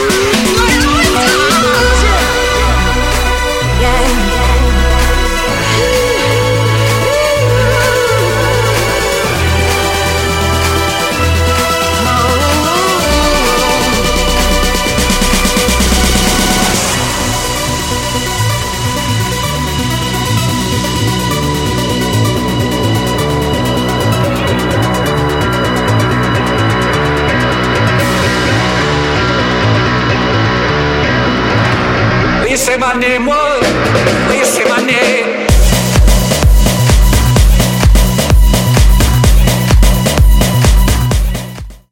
Из клубной музыки